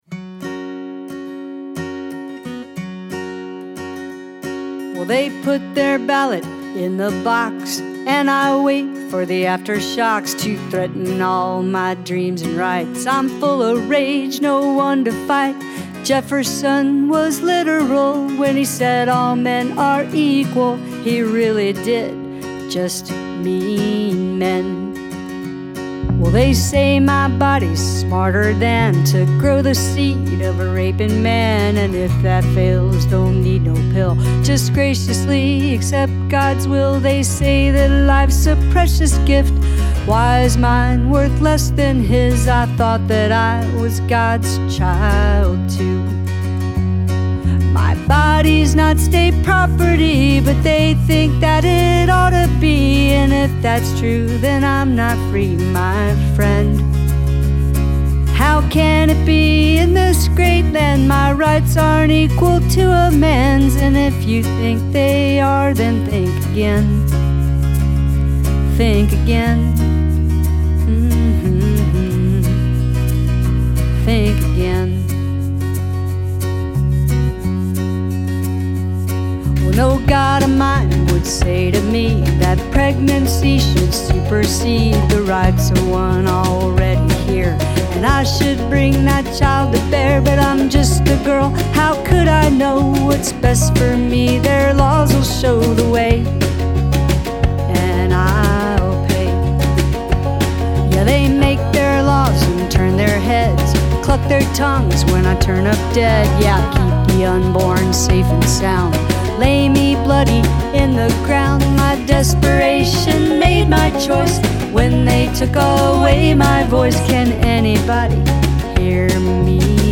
State Property, an original song